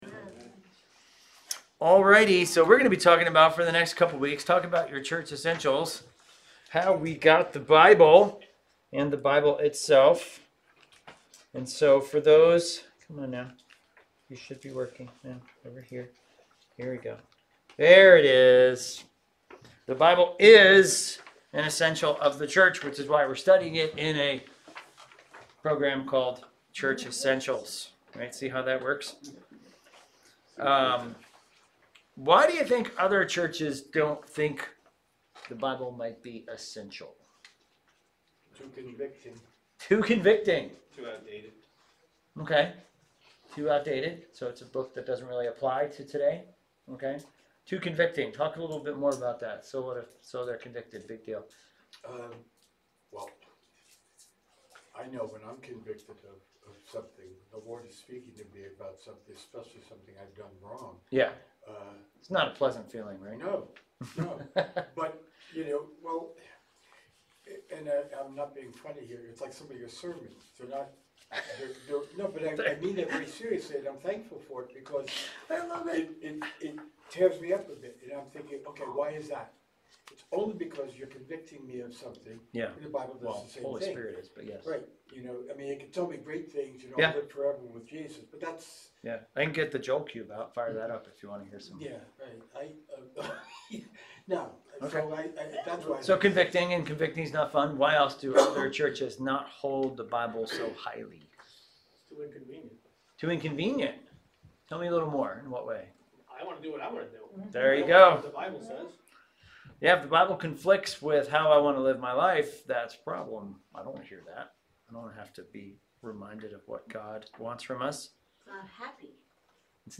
Highlands Bible Church Sermon Audio